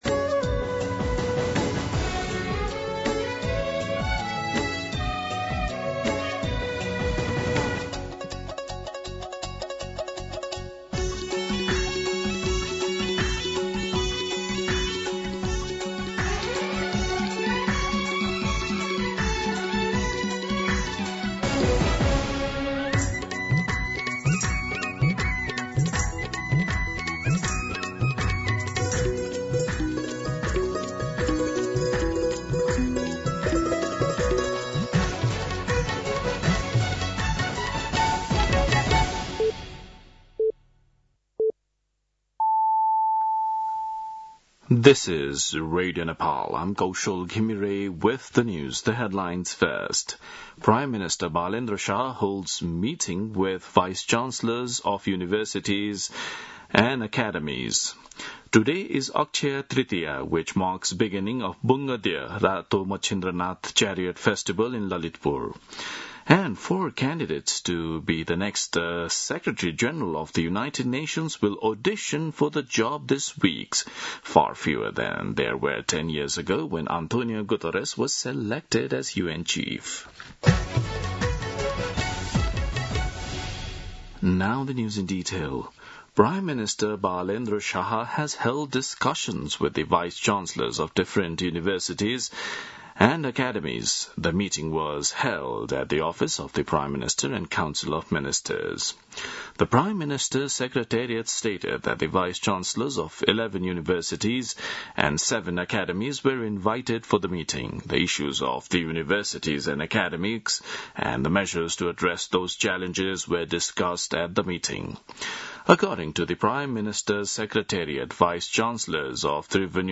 दिउँसो २ बजेको अङ्ग्रेजी समाचार : ७ वैशाख , २०८३
2pm-English-News-07.mp3